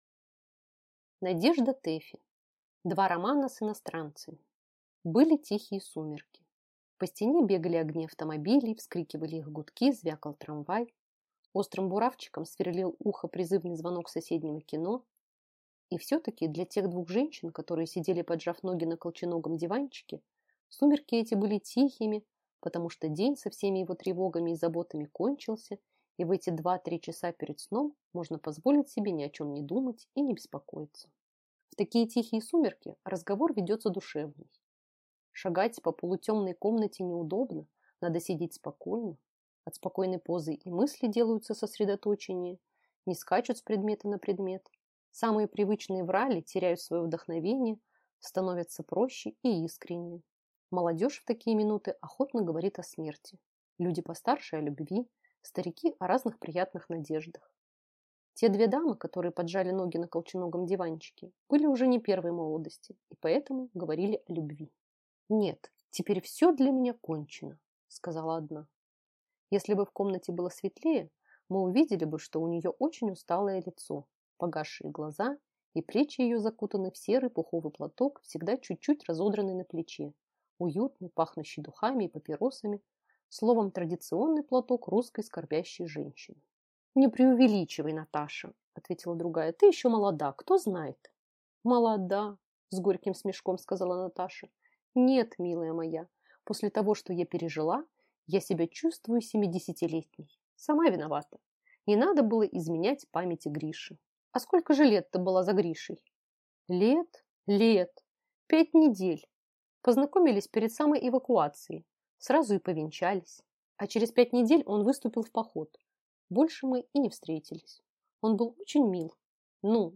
Аудиокнига Два романа с иностранцами | Библиотека аудиокниг
Прослушать и бесплатно скачать фрагмент аудиокниги